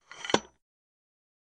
Ножка штатива или как ее правильно назвать